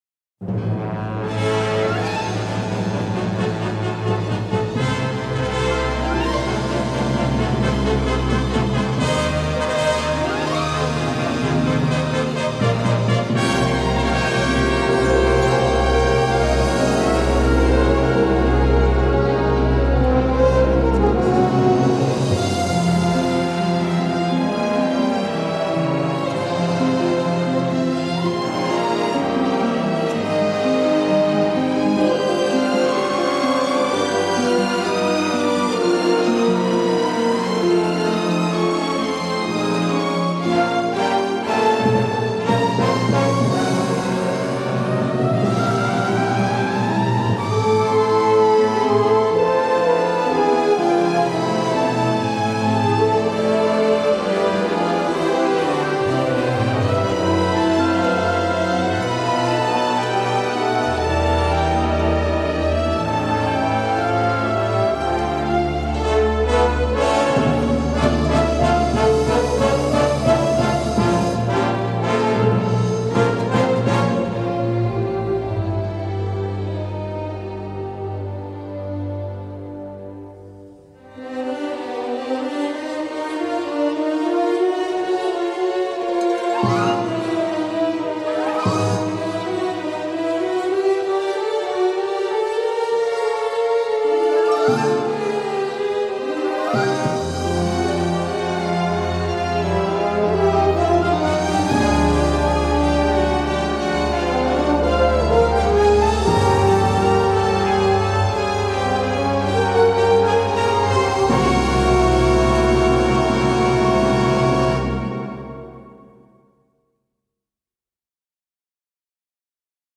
Comédie, action, exotisme, il y en a pour tous les goûts.